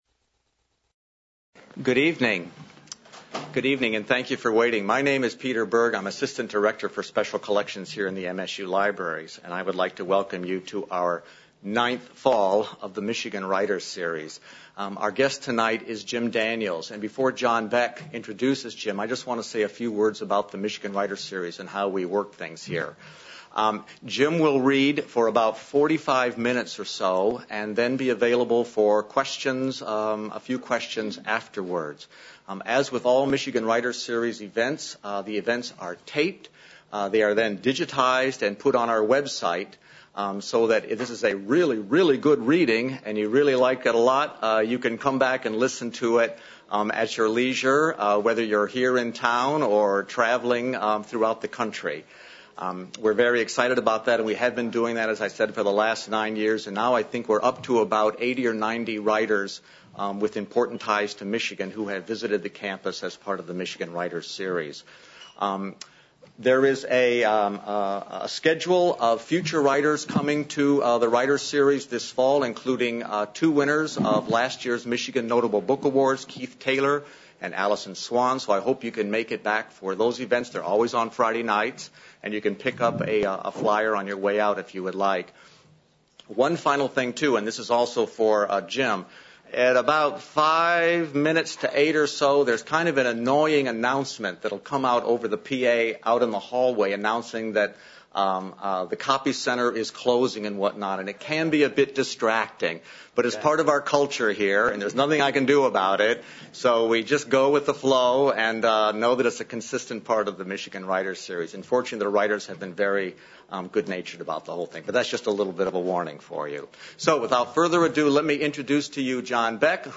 reads from his works and answers questions from audience at the Michigan Writers Series